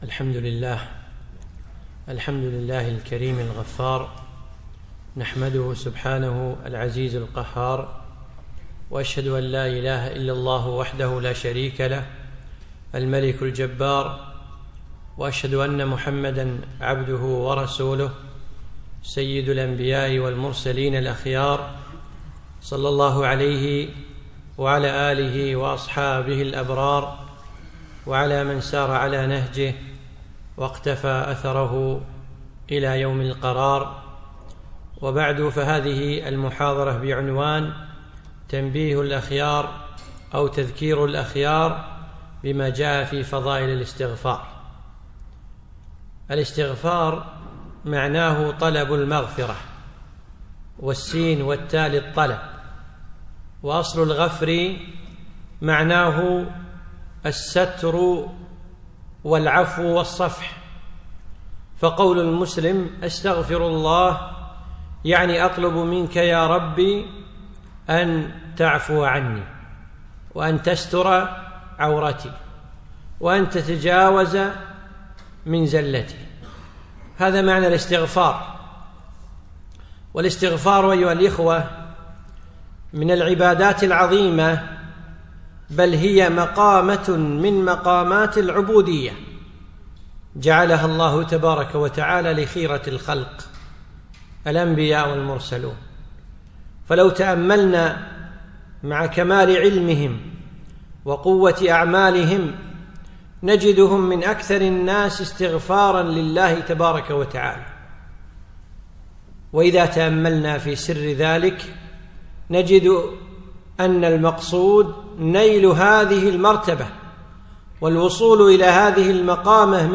يوم الجمعة 4 جمادى الأخر 1438 الموافق 3 3 2017 في مسجد الهاجري الجابرية